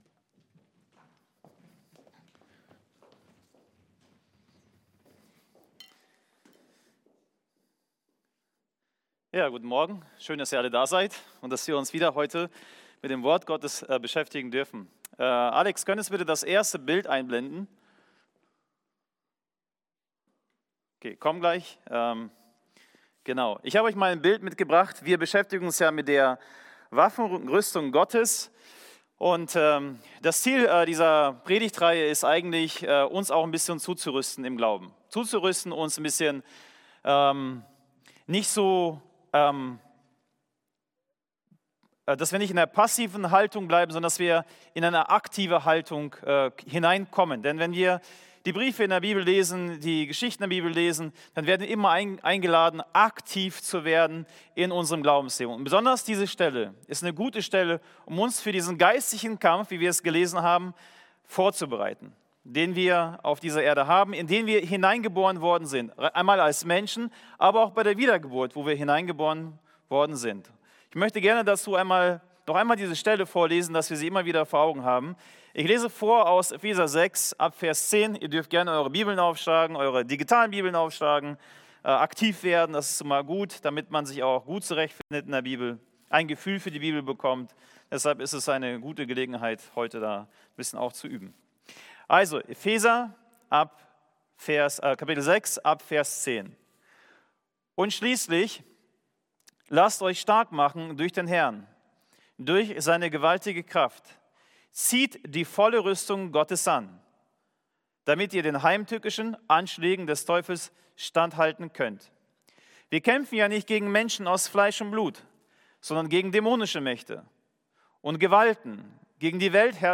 Schild des Glaubens ~ Casinogemeinde Predigten Podcast